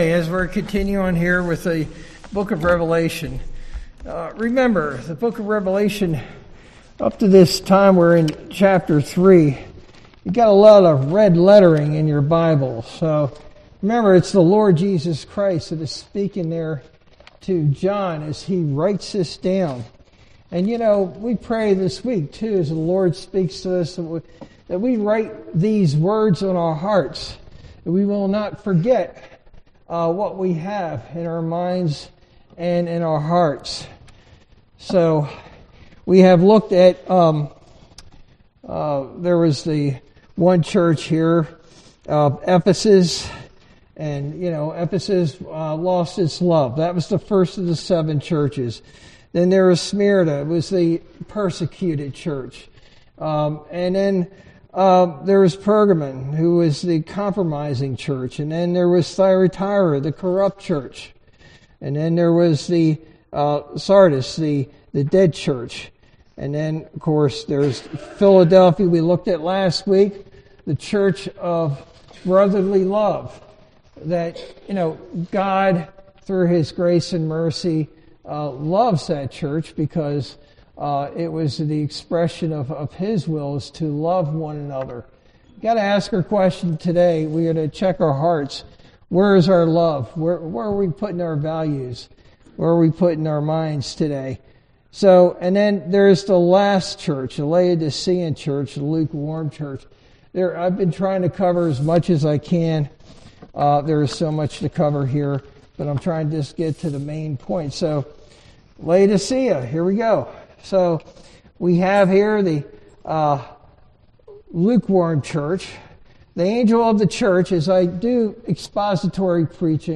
Sermon verse: Revelation 3:14-22